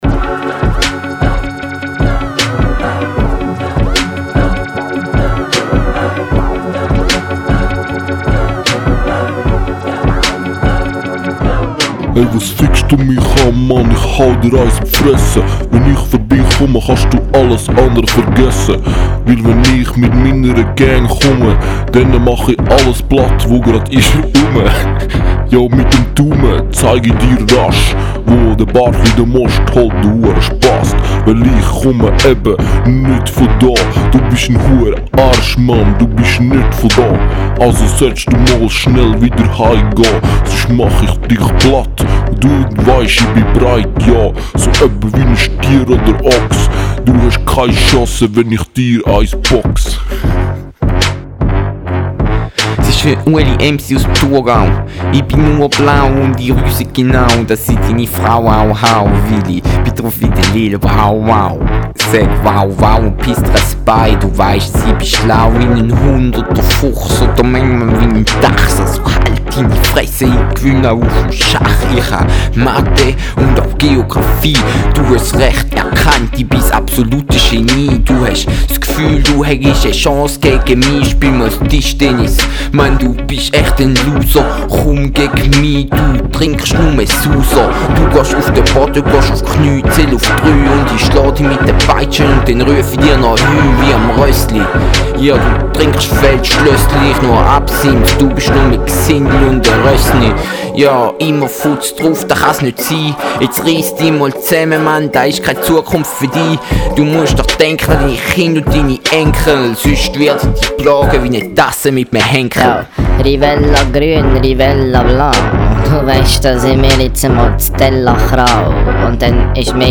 Tischtennis Freestyle
im ZZ Studio.